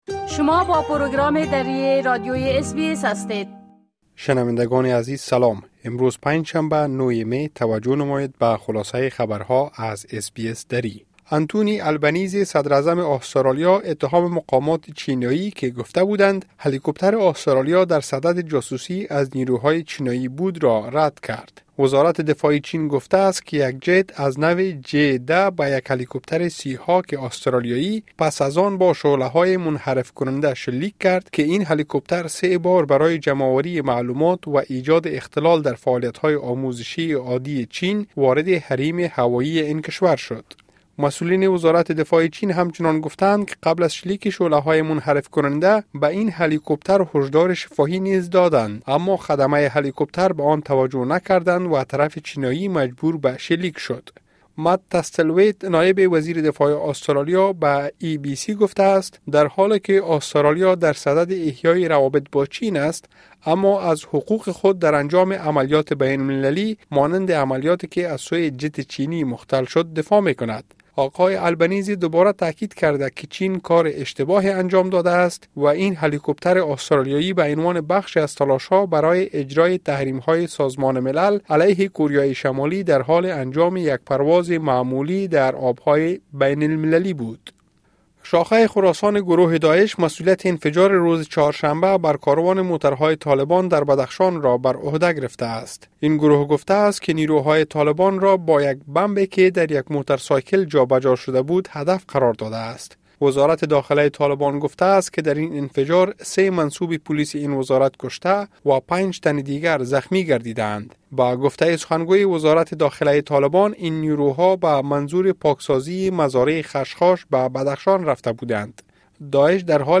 خلاصۀ مهمترين اخبار روز از بخش درى راديوى اس بى اس|۹ می ۲۰۲۴